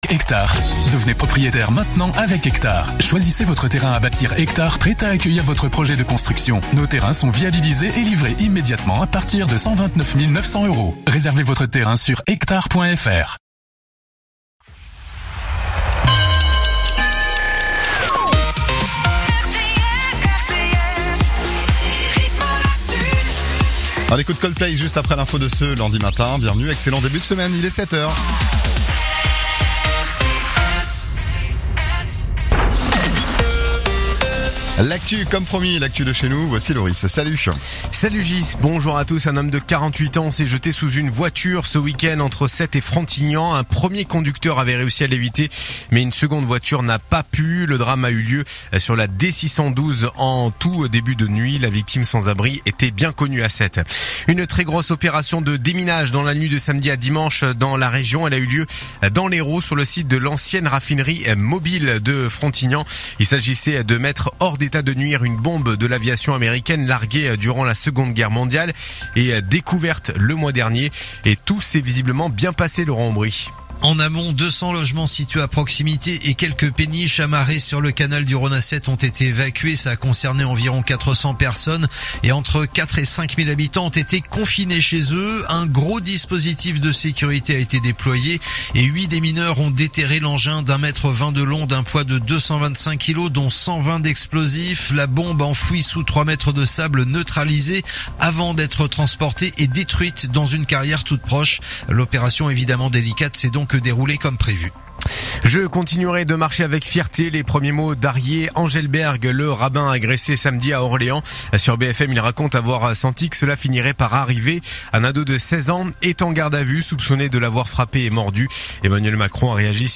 Écoutez les dernières actus de l'Hérault en 3 min : faits divers, économie, politique, sport, météo. 7h,7h30,8h,8h30,9h,17h,18h,19h.